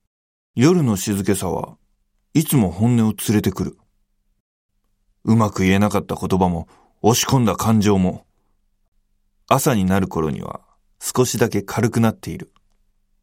ボイスサンプル
朗読